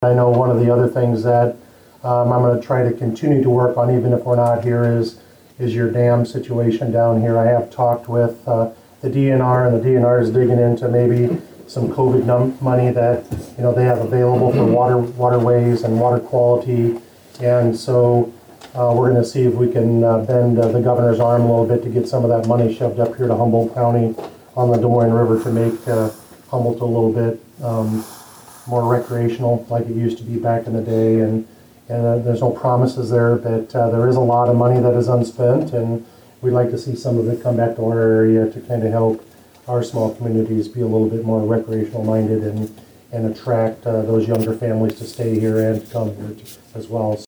The event was held at Humboldt City Hall.